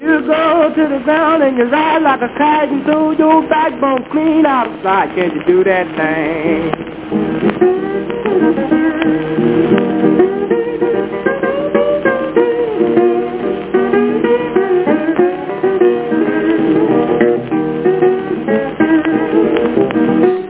переходящий в фальцет вокал в сопровождении слайд-гитары.